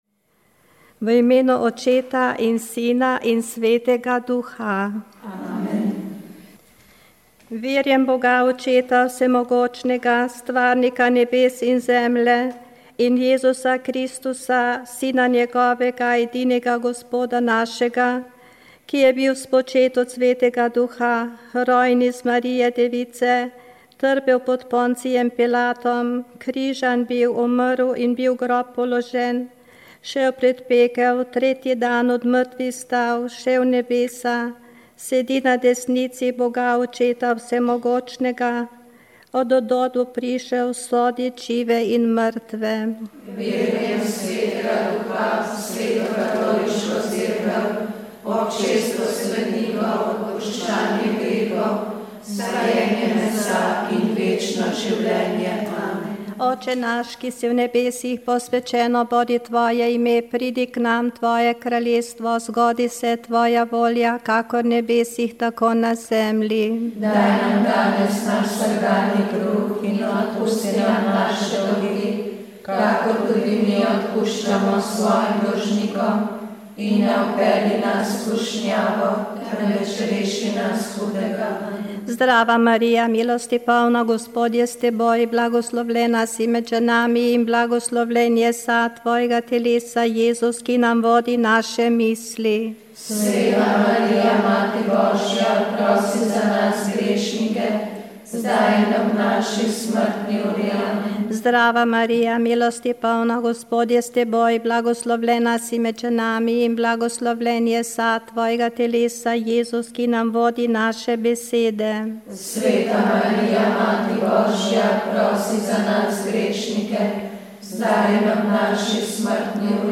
Molile so redovnice - Hčere krščanske ljubezni (Usmiljenke).